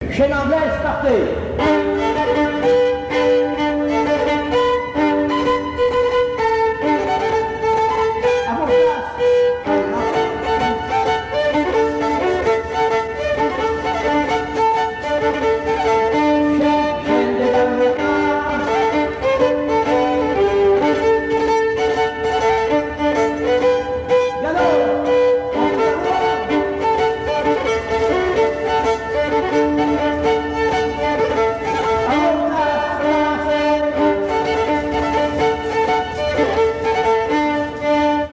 danse : quadrille : chaîne anglaise
Pièce musicale inédite